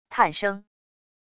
叹声wav音频